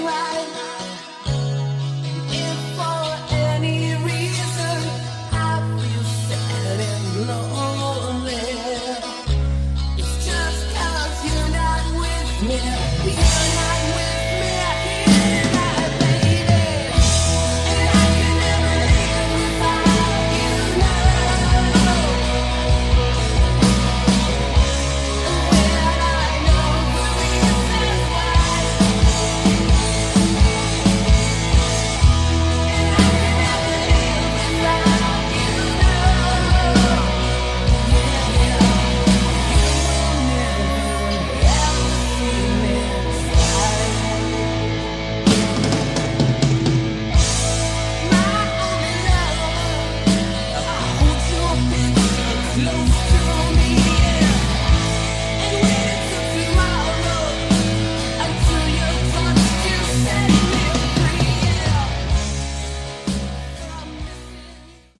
Category: Hard Rock
Lead Vocals, Rhythm Guitars
Lead Guitars
Bass
Drums
Good songs, shame about the production.